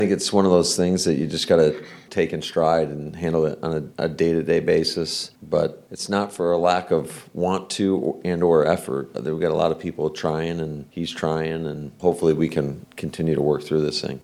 (LEARFIELD) – There was some good news coming out of Packers coach Matt LaFleur’s meeting with the media on Monday.